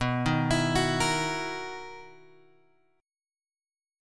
B Major 11th
BM11 chord {7 6 x 9 7 6} chord